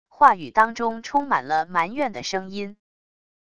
话语当中充满了埋怨的声音wav音频